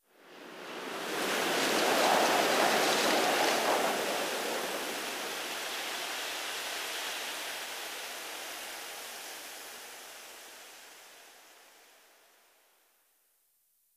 windwhistle2.ogg